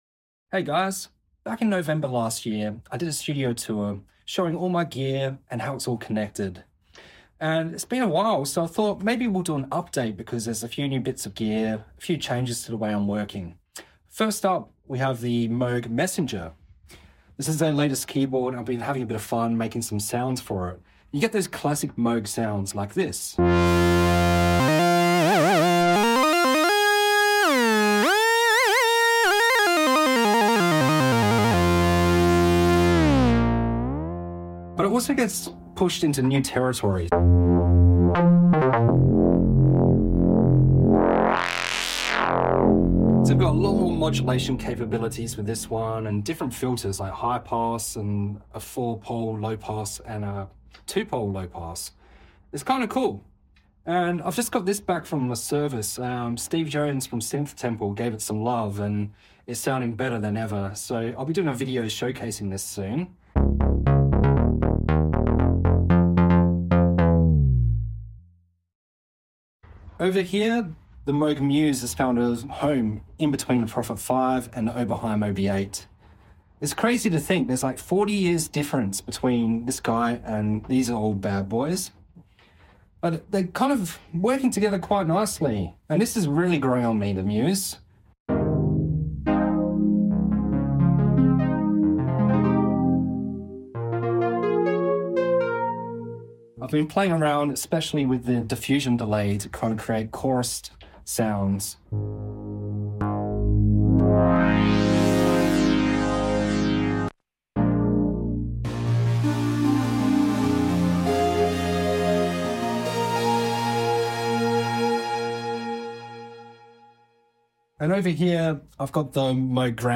A studio tour update showing sound effects free download